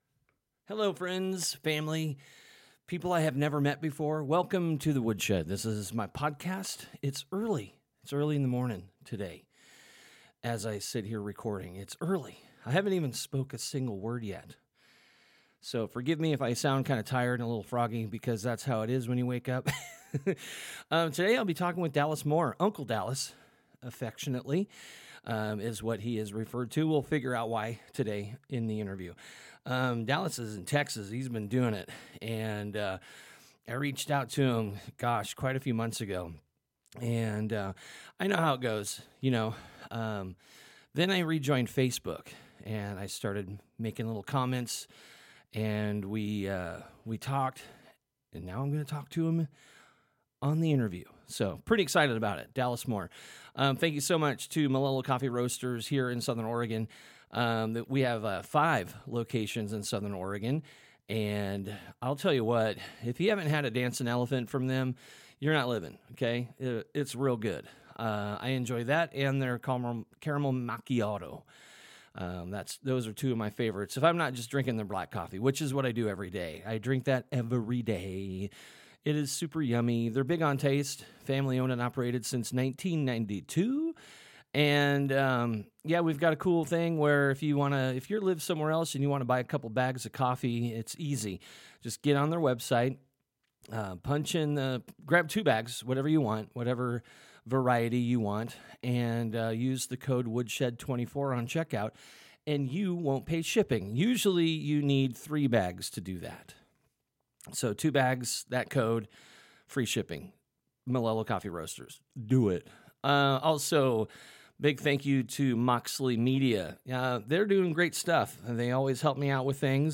All things music! Interviews with artists, dj's, producers, promotors, etc.